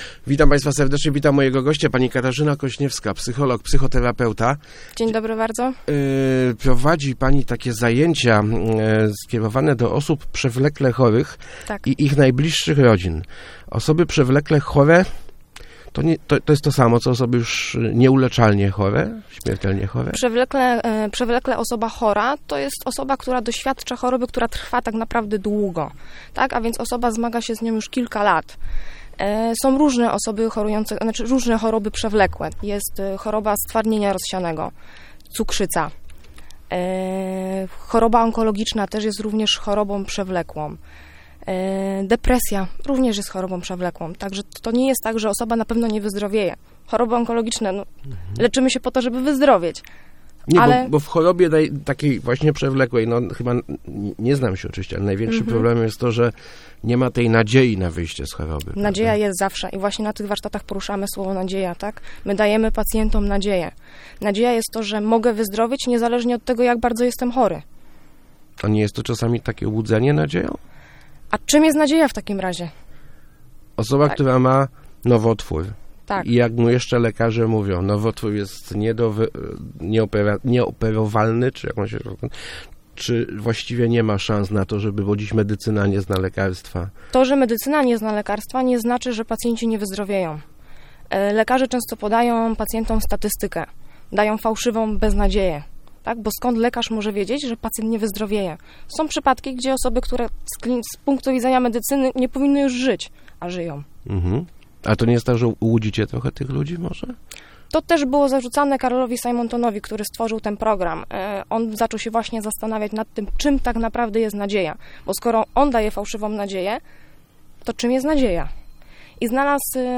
Start arrow Rozmowy Elki arrow Zdrowe myślenie w chorobie